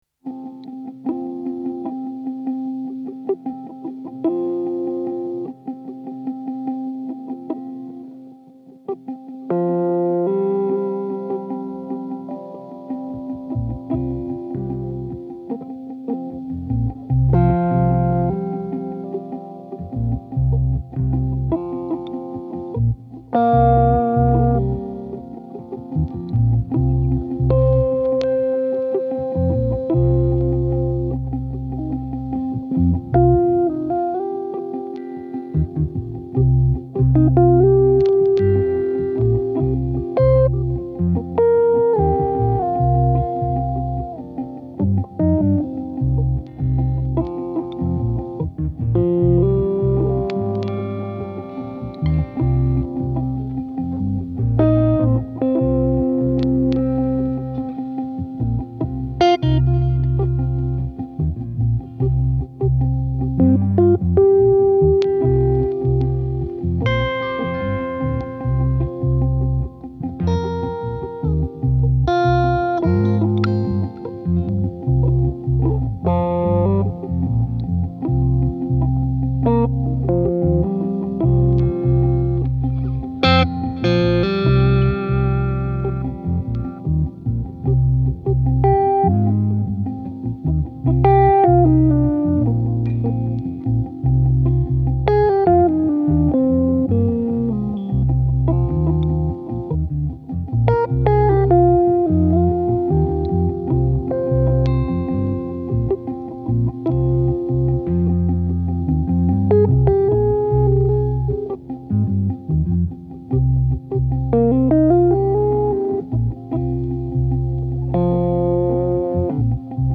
motoric vibes on the road